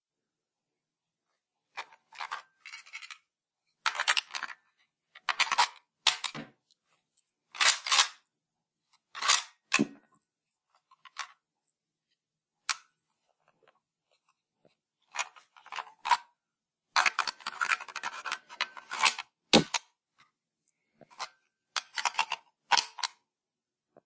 步枪子弹铿锵作响
描述：几个7.62步枪的空弹壳在坚硬的表面上铿锵作响，
标签： 战争 弹药 步枪 弹药 电影 墨盒 子弹
声道立体声